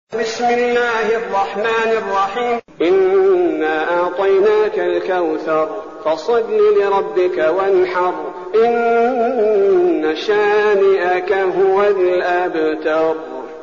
المكان: المسجد النبوي الشيخ: فضيلة الشيخ عبدالباري الثبيتي فضيلة الشيخ عبدالباري الثبيتي الكوثر